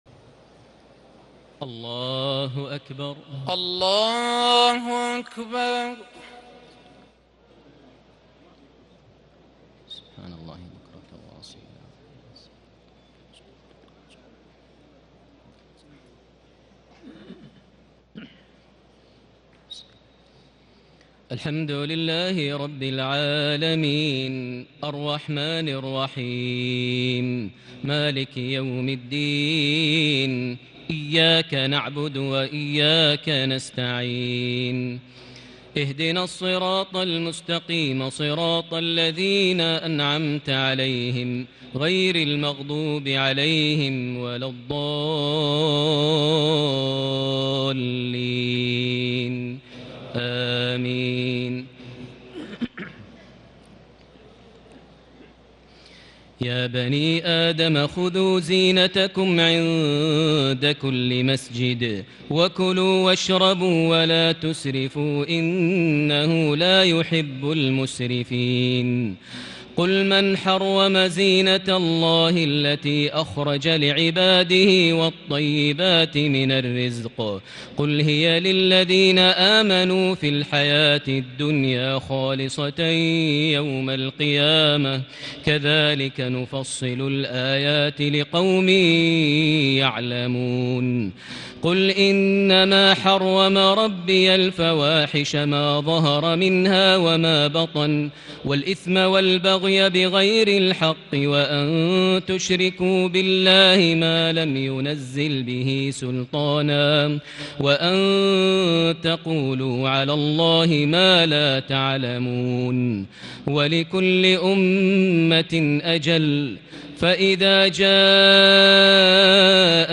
تهجد ليلة 28 رمضان 1440هـ من سورة الأعراف(31-93) Tahajjud 28 st night Ramadan 1440H from Surah Al-A’raf > تراويح الحرم المكي عام 1440 🕋 > التراويح - تلاوات الحرمين